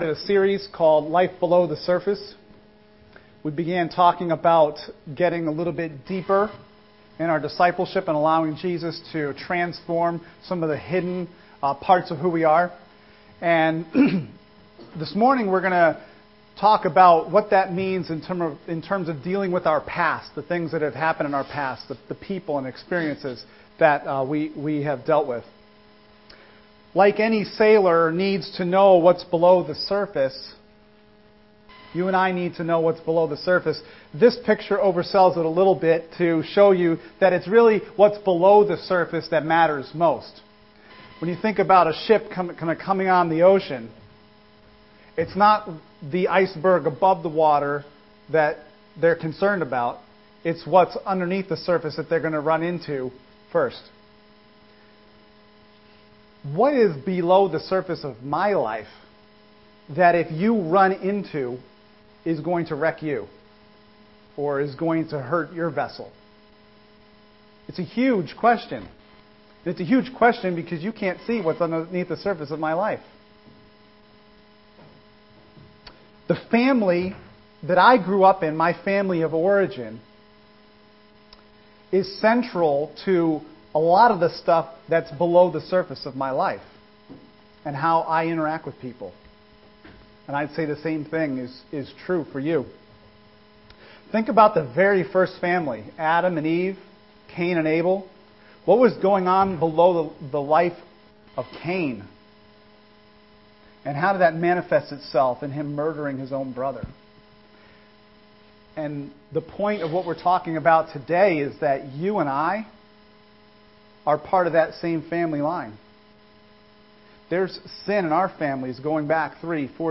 Life Below The Surface | Sermon Series | Crossroads Community ChurchCrossroads Community Church
( Sunday AM )